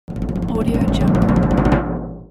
Литавры - чем повторить , примерчик прилагается
Ребят, а что есть из литавров НЕ пулемётящее? Нужно дробь нарисовать типа такой Попробовал контактовские родные — не то Вложения untitled 27oct_13-49.mp3 untitled 27oct_13-49.mp3 92,8 KB · Просмотры: 1.082